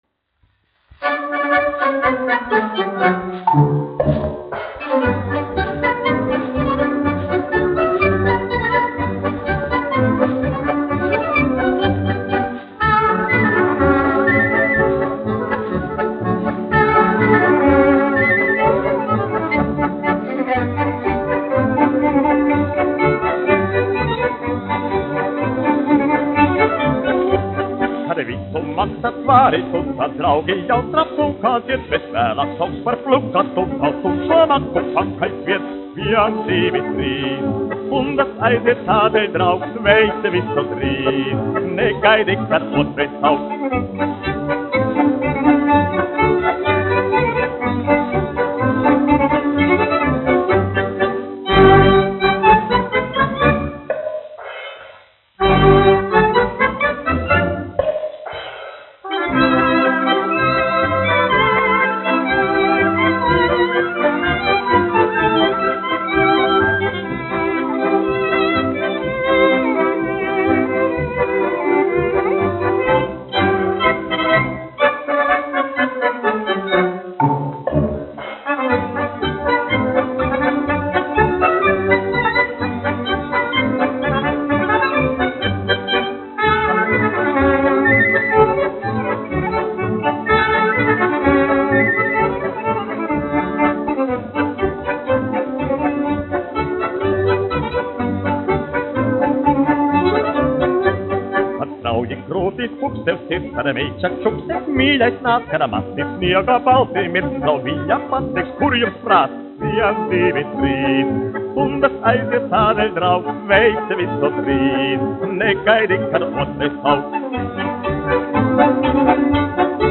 1 skpl. : analogs, 78 apgr/min, mono ; 25 cm
Polkas
Populārā mūzika -- Latvija
Skaņuplate